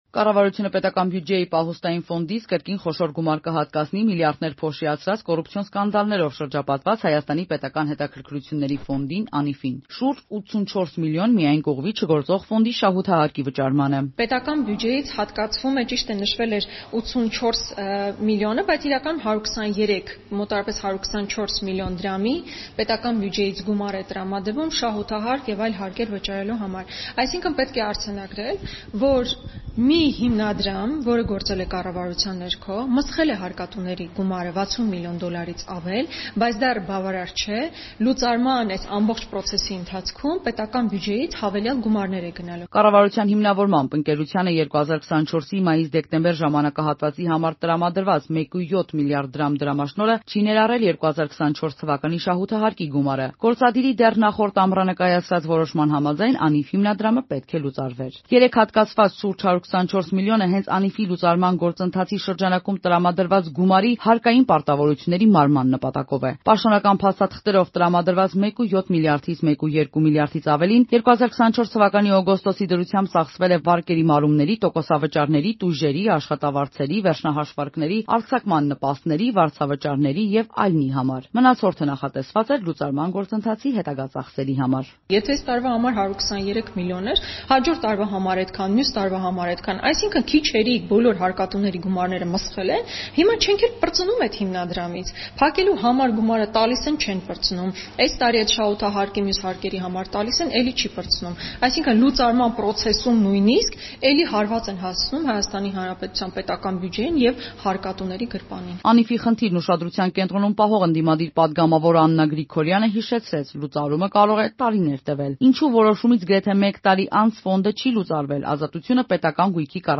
Ամբողջական տարբերակը՝ «Ազատության» ռեպորտաժում.